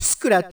DJ School sounds
scratchoHey1.ogg